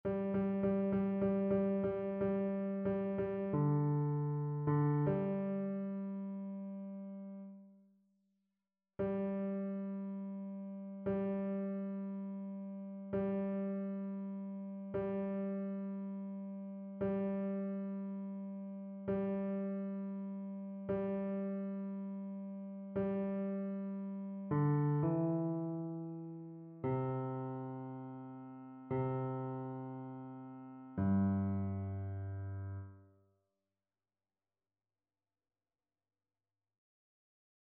Basse
annee-abc-fetes-et-solennites-saint-joseph-psaume-88-basse.mp3